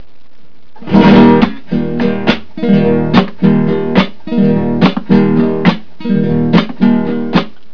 I have played each slowly and made them into soundfiles, and then repeated the first example at a typical "La Bamba" tempo.
The others are variations that are easily figured out once you can play the single